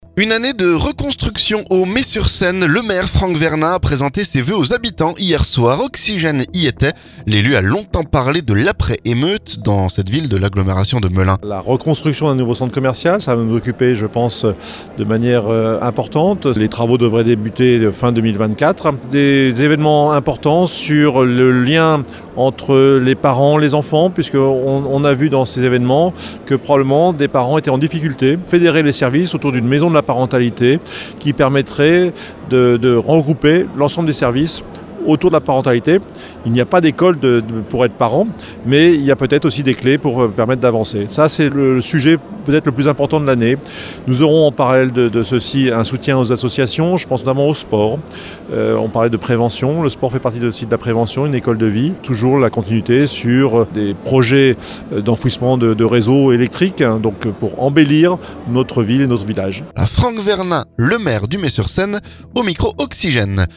Le maire Franck Vernin a présenté ses vœux aux habitants mercredi soir.